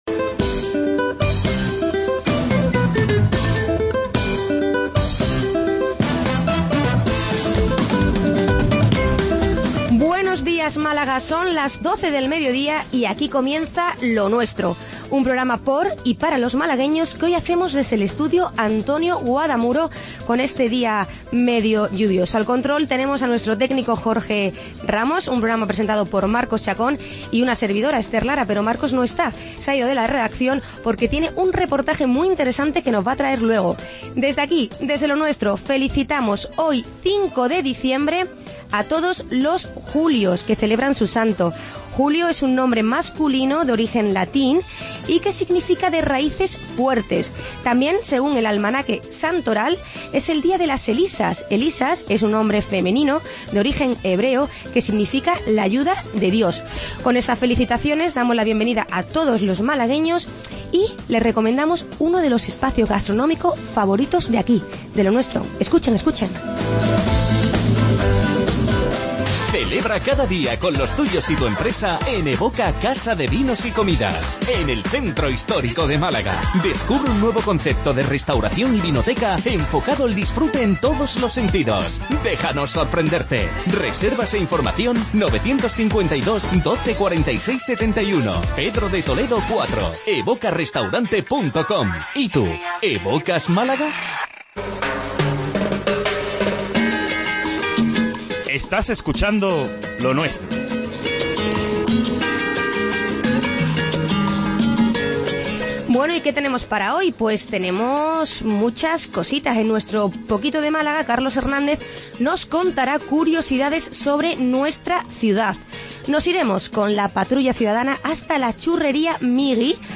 Magazine diario